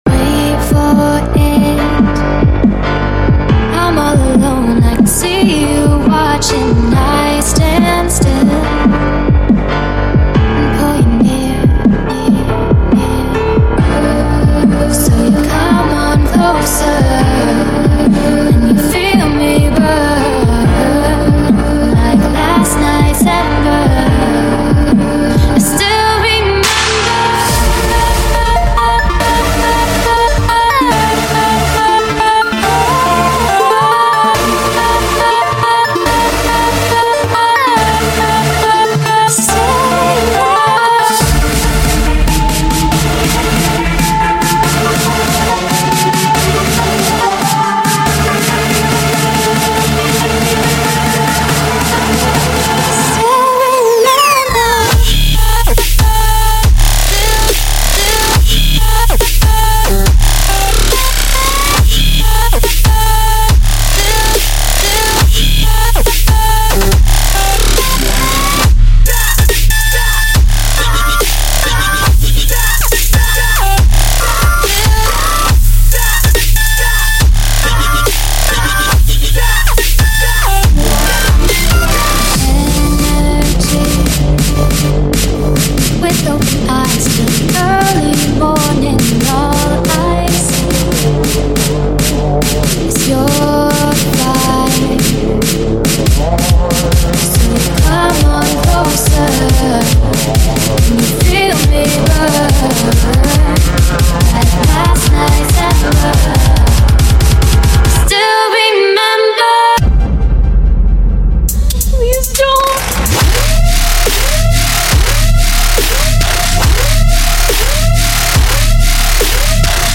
BPM70-280
MP3 QualityMusic Cut